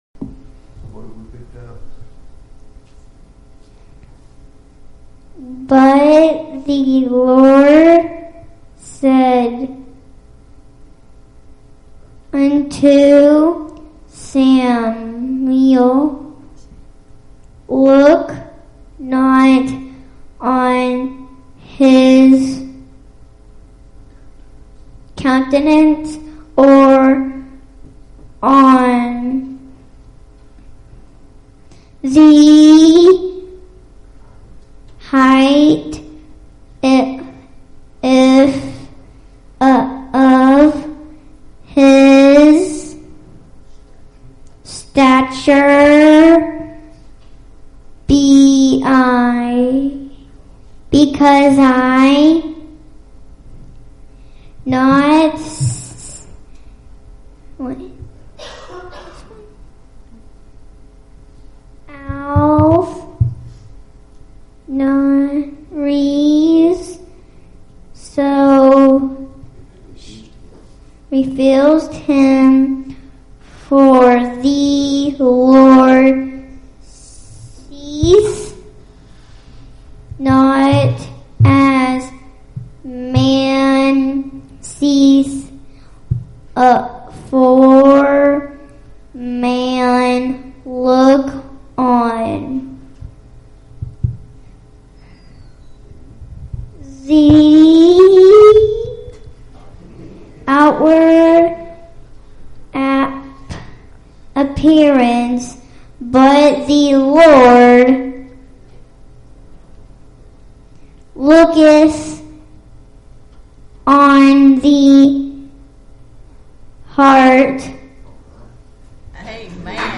Isaiah 43:1-2 Service Type: Sunday Evening Services Topics